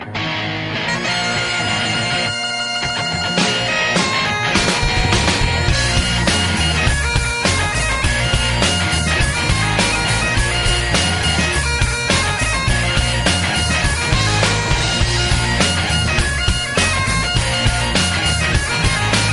Ringtones Category: Guitar